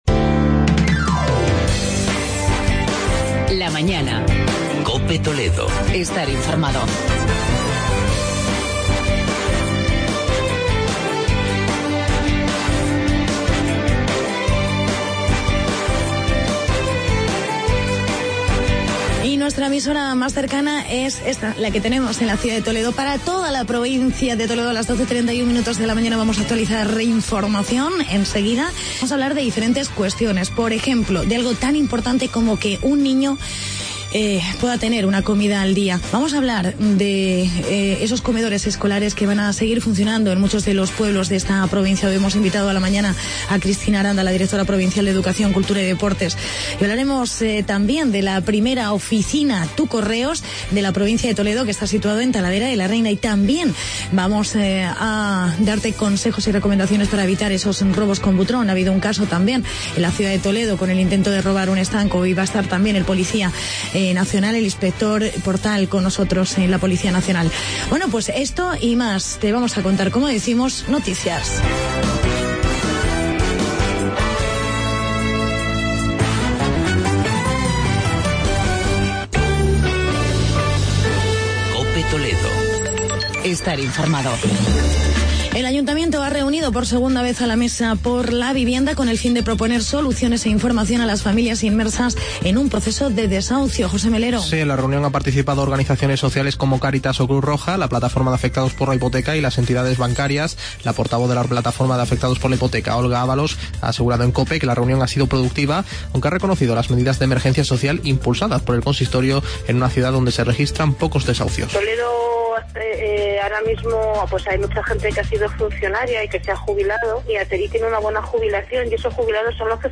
Hablamos con la Directora provincial de Educación Cristina Aranda sobre los comedores escolares abiertos en verano. Entrevista